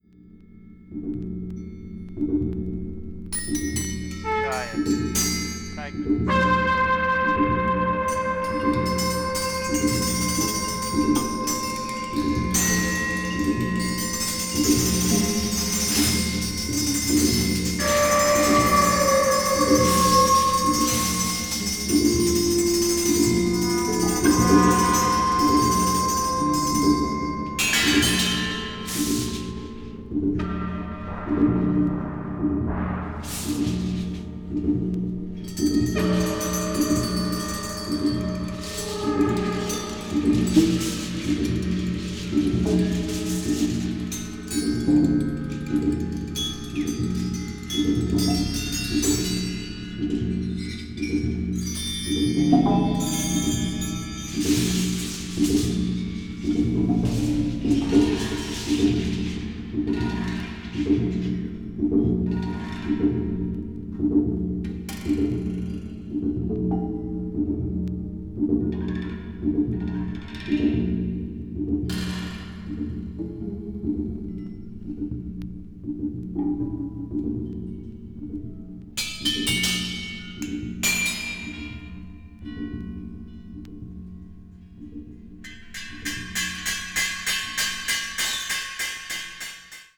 A1/A2は作曲家自身がプリレコーディングしたテープが用いられています。